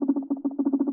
boat_small_cartoon_propeller_stuttering.wav